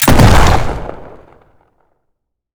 gun_shotgun_shot_02.wav